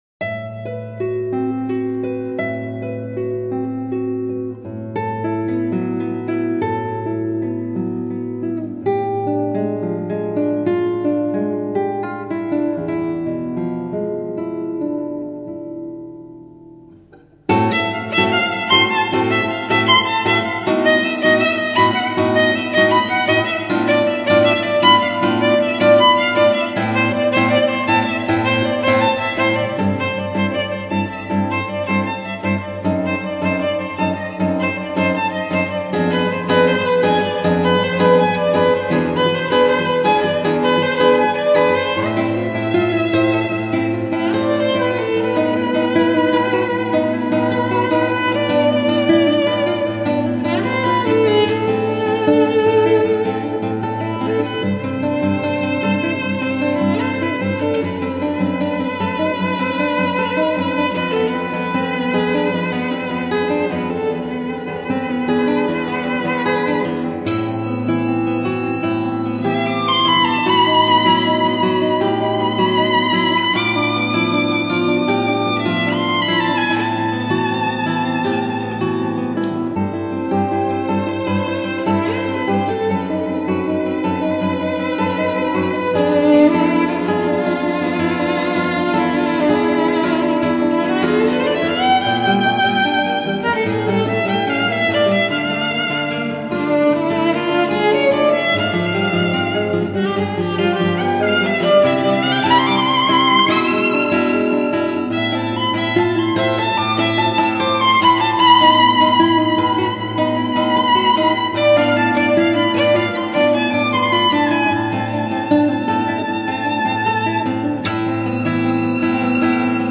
小提琴--西班牙探戈